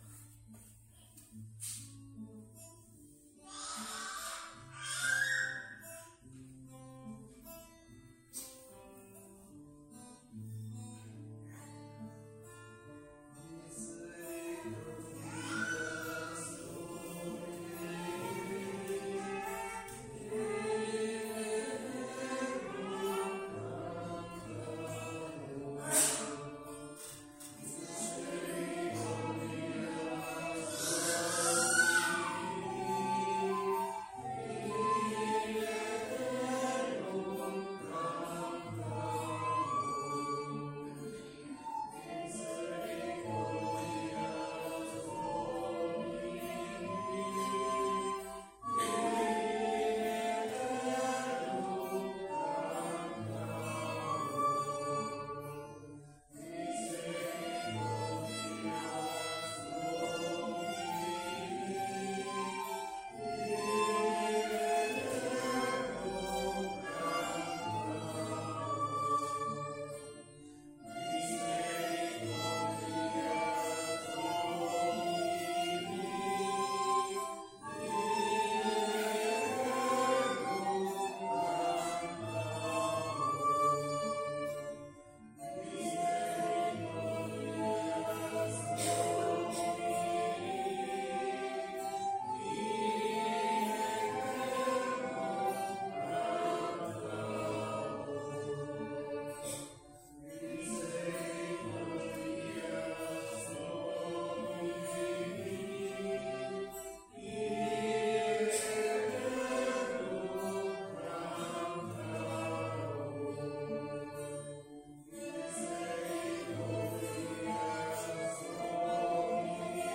Pregària de Taizé a Mataró... des de febrer de 2001
Ermita de Sant Simó - Diumenge 29 de gener de 2023